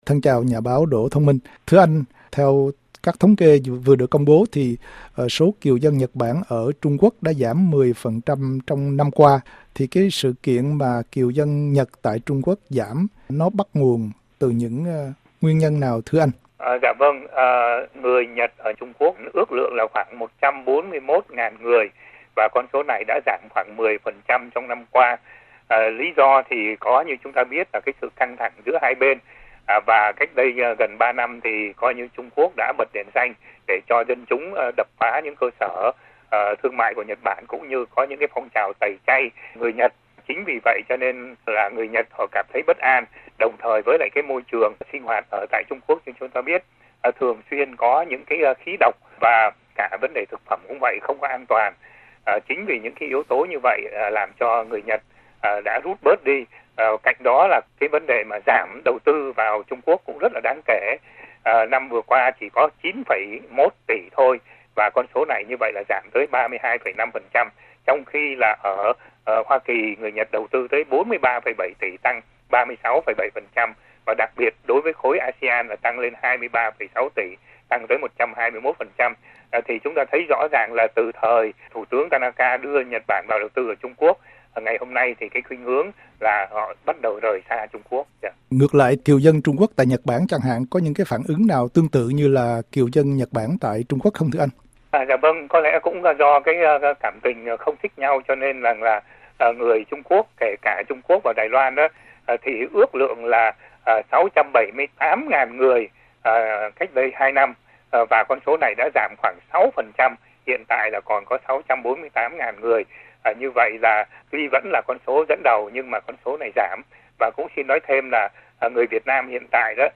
TAGS: CHÂU Á - NHẬT BẢN - TRUNG QUỐC - CHÂU Á-THÁI BÌNH DƯƠNG - CĂNG THẲNG - TRANH CHẤP - CHỦ NGHĨA DÂN TỘC - PHỎNG VẤN